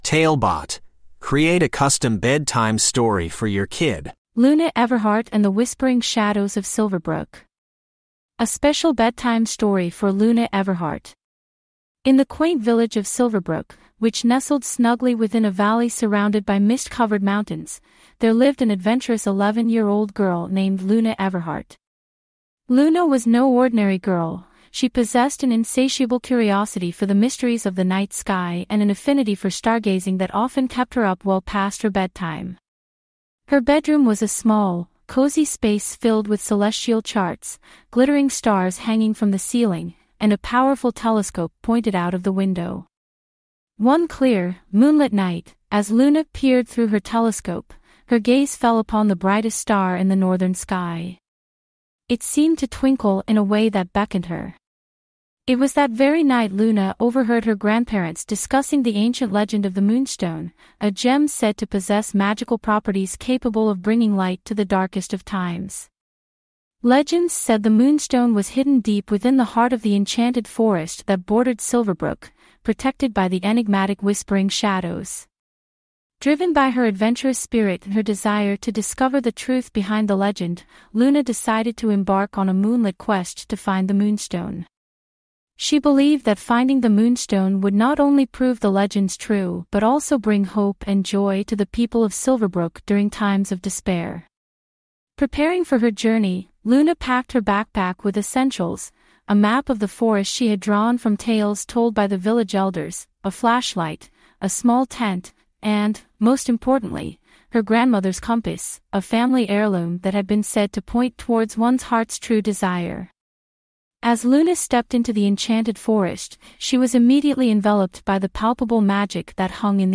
TaleBot Bedtime Stories